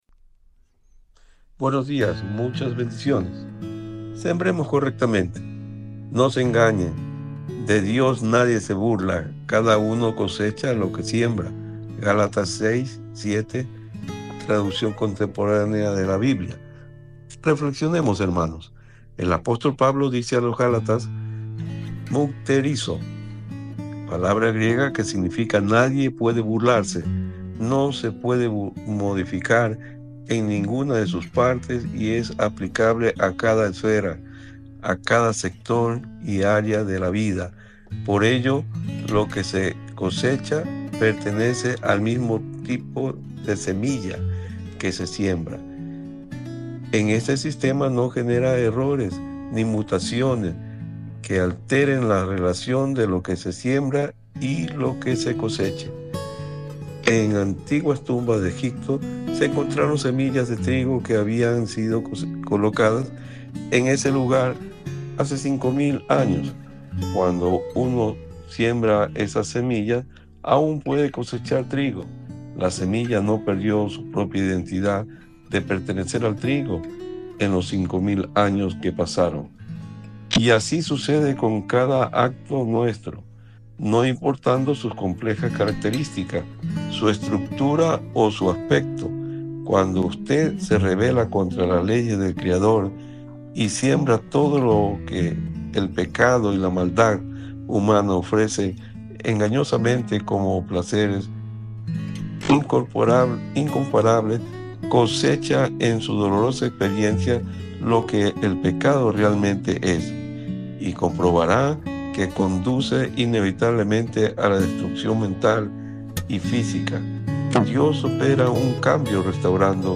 Escuchar Devocional